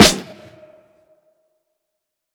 wtey_Snr.wav